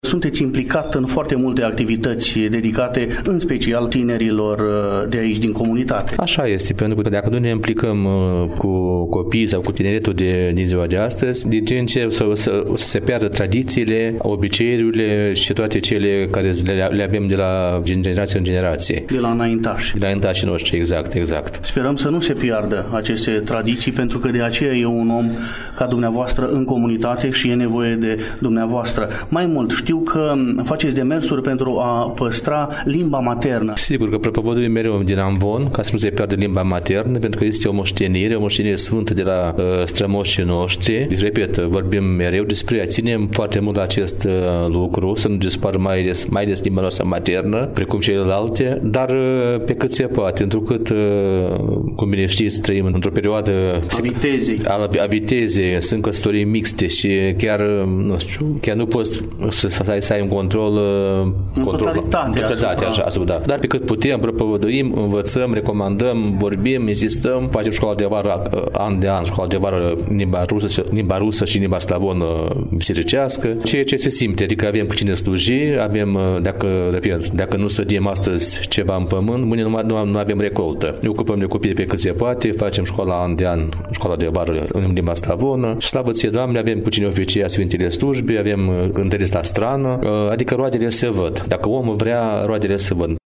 Astăzi relatăm de la FESTIVALUL-CONCURS NAȚIONAL ȘCOLAR DE INTERPRETARE DE NOTE PSALTICE ȘI STIHURI DUHOVNICEȘTI „IOACHIM IVANOV”, ediția a VI-a, de la Galați, eveniment desfășurat în perioada 6-9 august, în incinta Teatrului Dramatic „Fani Tardini”, de pe strada Domnească, Numărul 59.